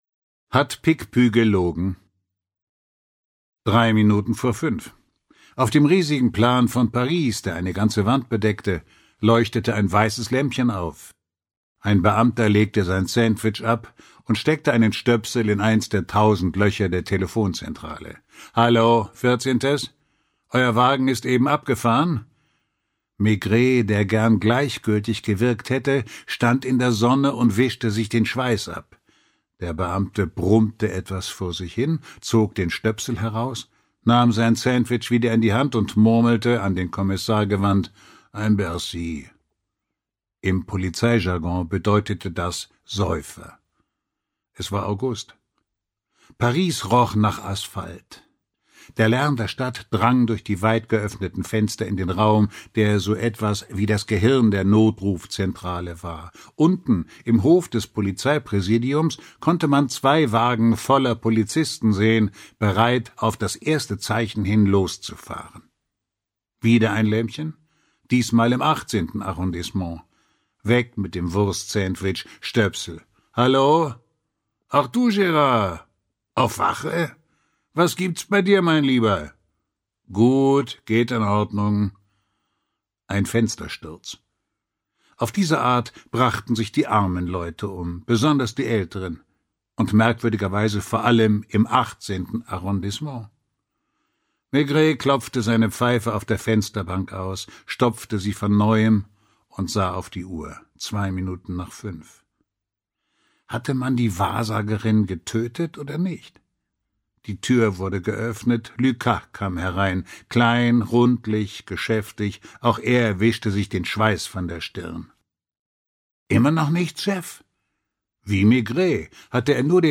Walter Kreye (Sprecher)
Ungekürzte Lesung